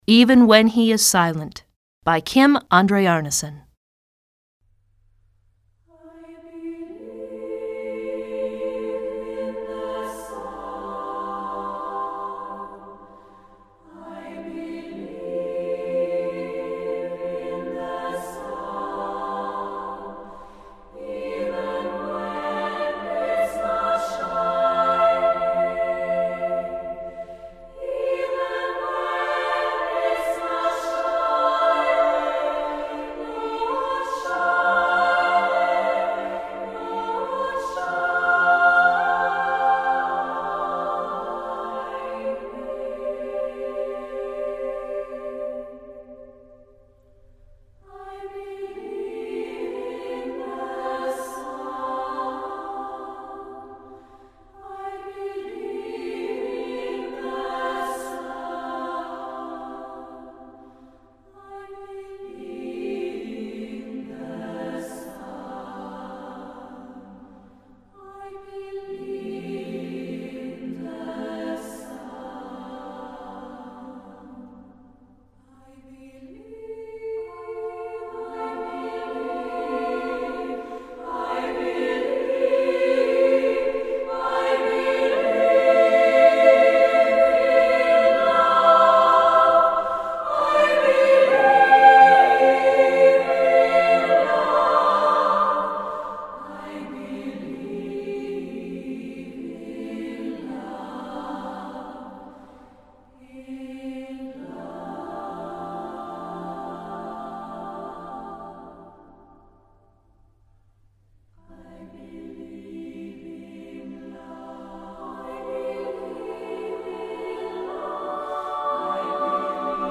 Choeur Mixte SATB a Cappella